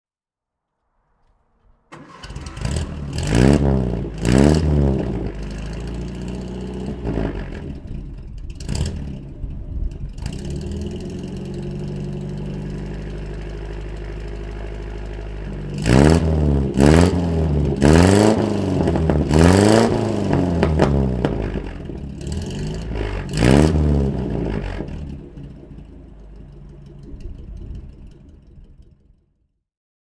Austin A55 Cambridge (1957) - Starten und Leerlauf